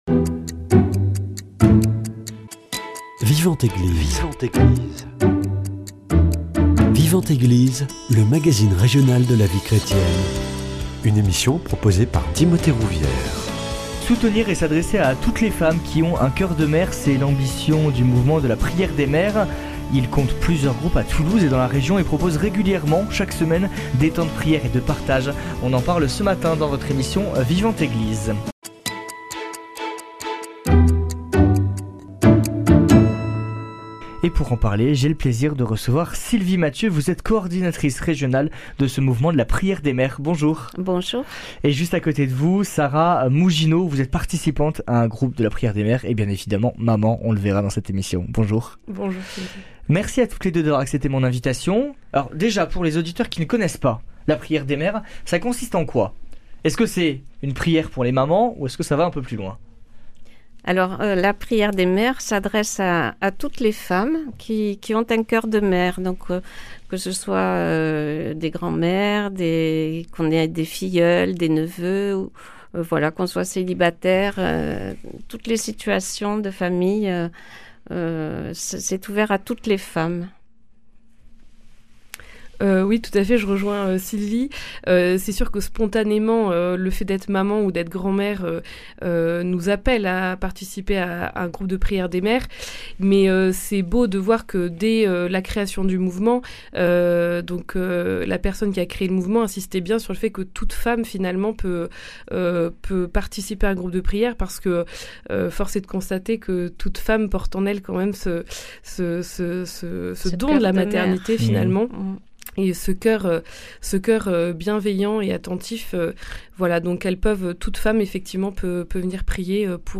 [ Rediffusion ] [Rediffusion] Soutenir et s’adresser à toutes les femmes qui ont un cœur de mère, c’est l’ambition de la prière des mères. L’association compte plusieurs groupes à Toulouse et dans la région et propose régulièrement des temps de prière et de partage.